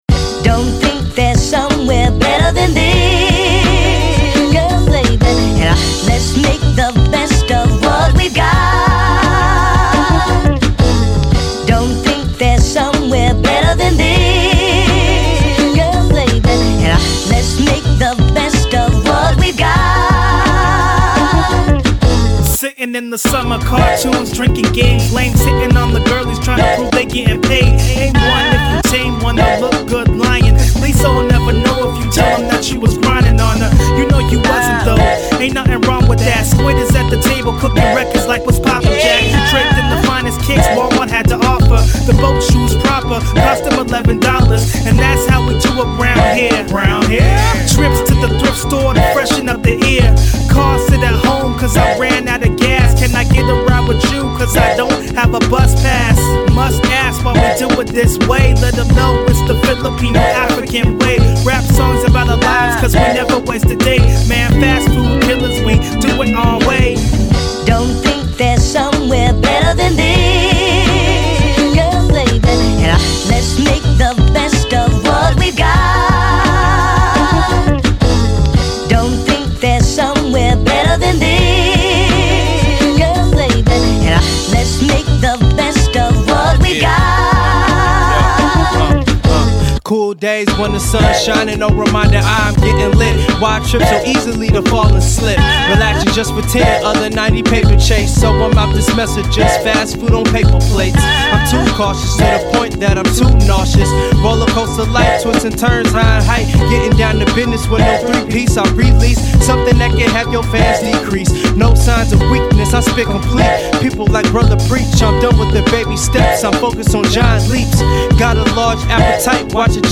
three man group from California